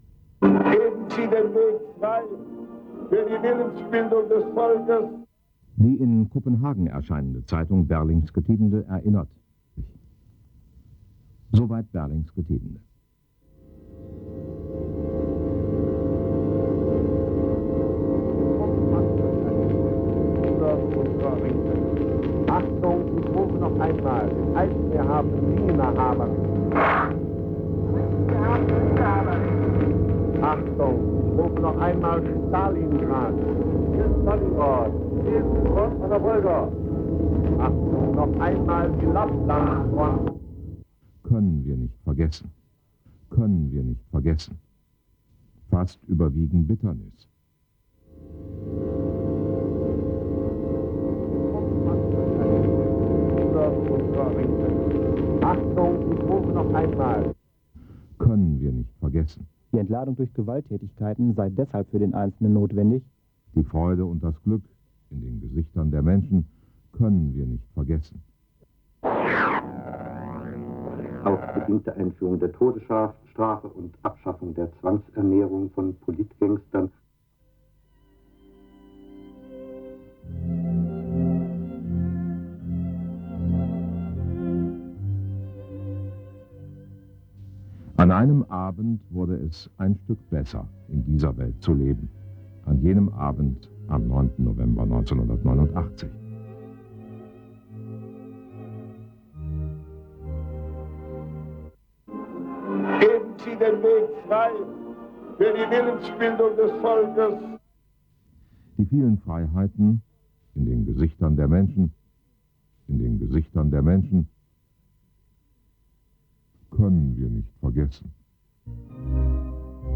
Vergessen - 9. November AudioCollage